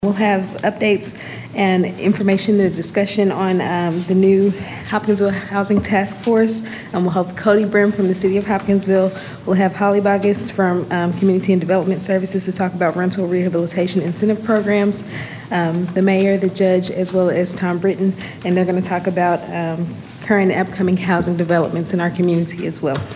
Christian County Fiscal Court opened Tuesday’s meeting celebrating “Month of the Military Child,” while proclaiming Wednesday, April 15, a “Purple Up Day” across the community.